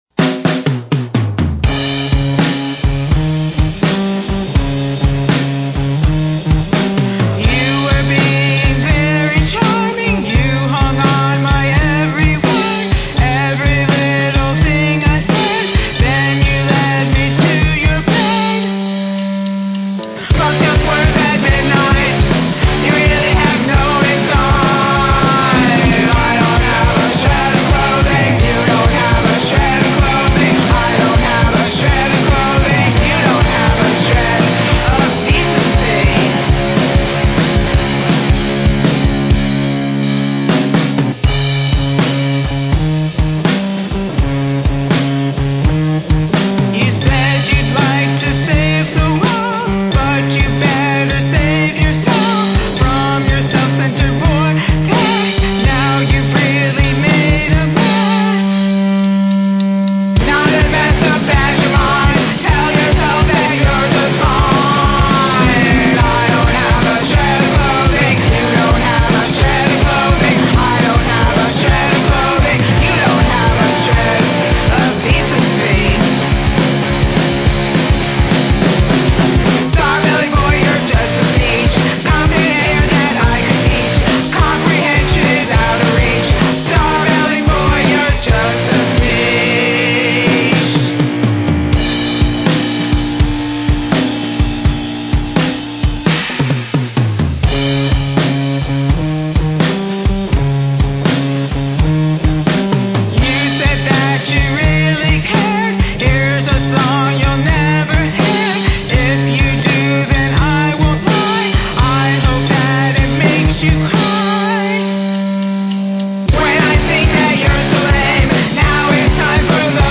Guitar and Lead Vocal
Drums, Percussion, and Backing Vocal